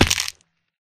fallbig1.ogg